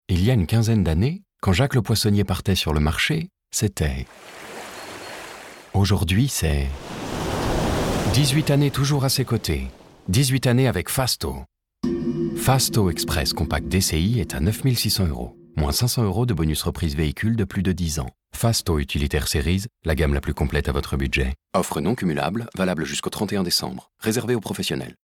Voix Off, voix masculine, homme, voix grave, voix très grave, voix chaude, voix paternelle, voix sécurisante, voix douce, voix puissante.
Sprechprobe: eLearning (Muttersprache):